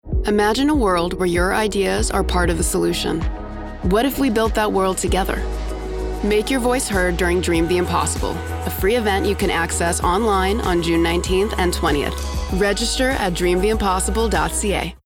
Publicité (Desjardins) - ANG